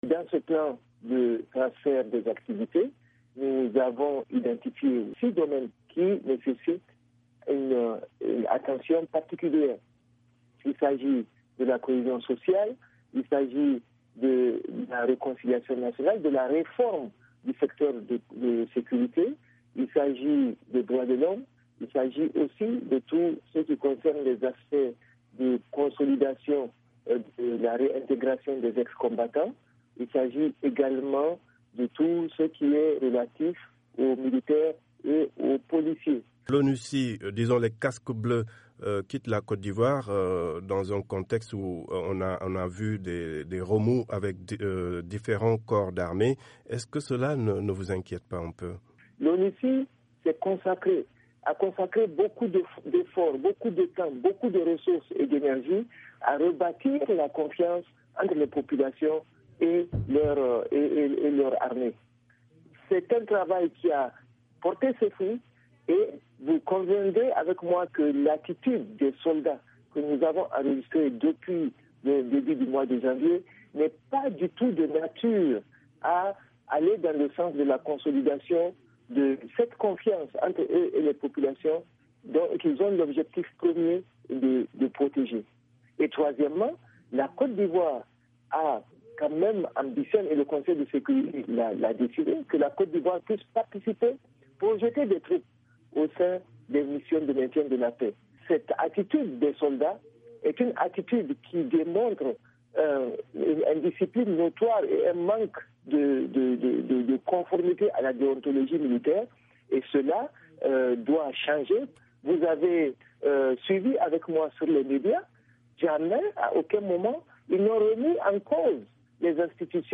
Interrogée par VOA Afrique, Mme Mindaoudou regrette néanmoins la série de mutineries que connait la Cote d’Ivoire depuis le mois de janvier, invitant les soldats mécontents à user de moyens légaux.